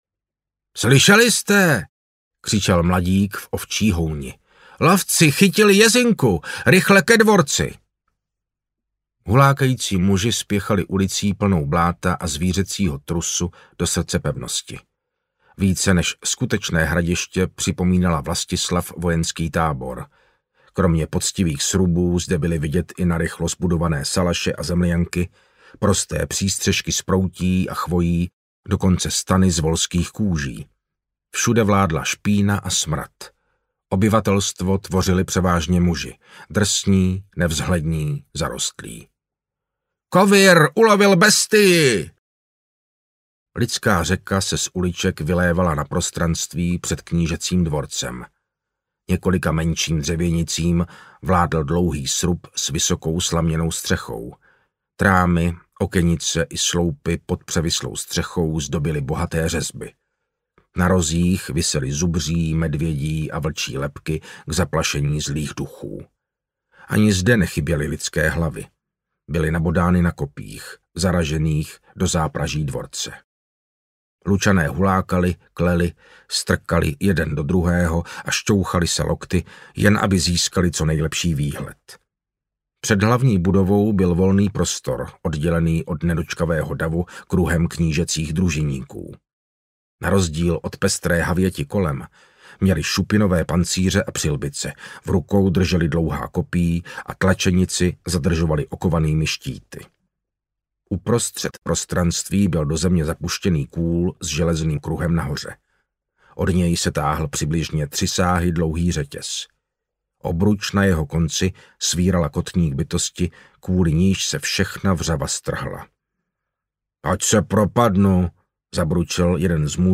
Radhostův meč audiokniha
Ukázka z knihy
• InterpretMartin Finger